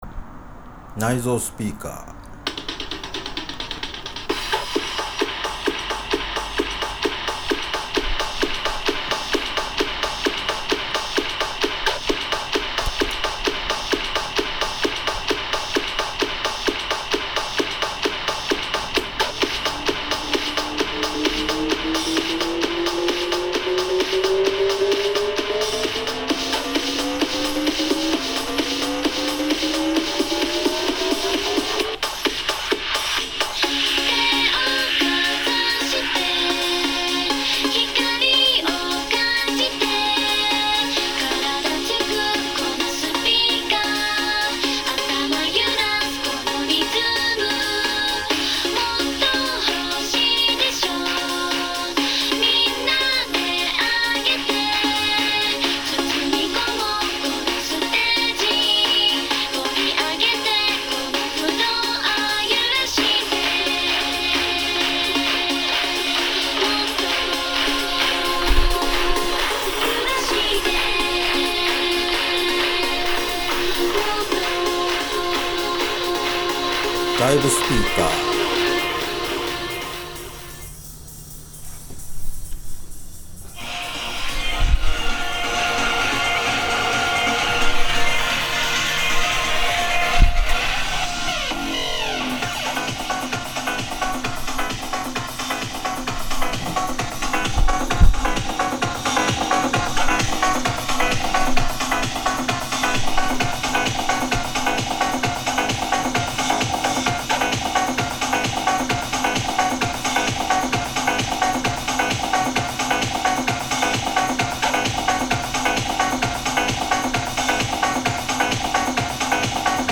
セラミックカートリッジの音は、周波数特性が右肩上がりのため、低音が出にくく高音がキンキンして聴きづらい音質です。
（アナログレコード版）
それでもチープな感じなのは、ノイズも強調されてしまうのと、共鳴が起こっているからです。